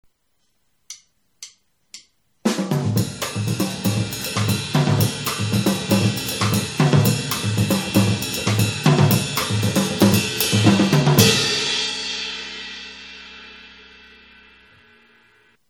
DRUM SET